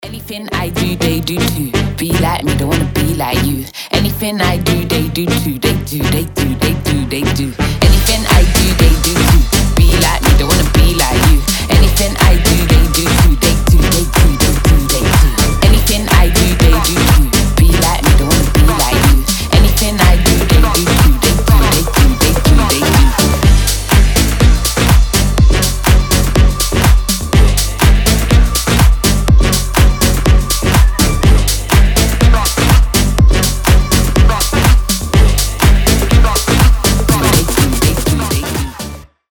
• Качество: 320, Stereo
ритмичные
громкие
Electronic
EDM
Стиль: future house